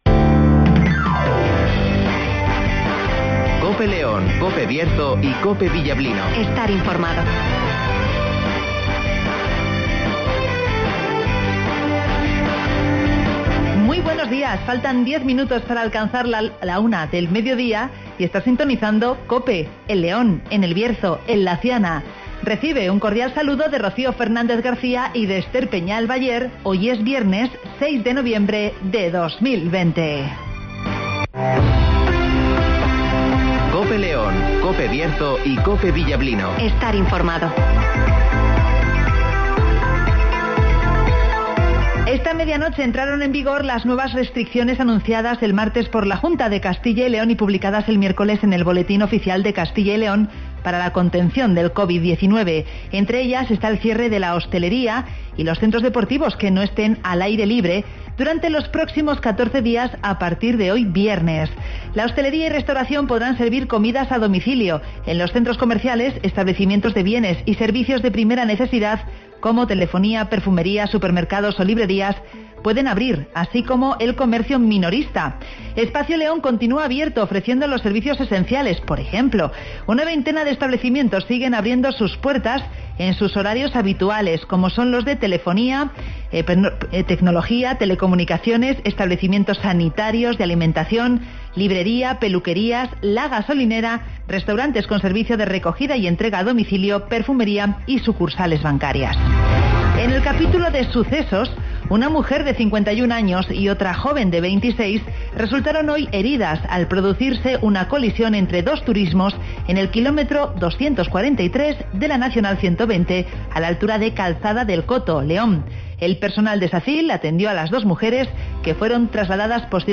Avance informativo, El Tiempo (Neucasión) y Agenda (Carnicerias Lorpy)